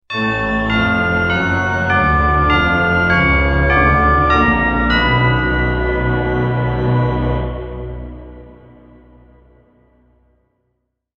Dark Organ Transition Sound Effect
This scary organ transition melody sound effect features a dark, haunting glissando perfect for horror, thriller, or gothic scenes.
Dark-organ-transition-sound-effect.mp3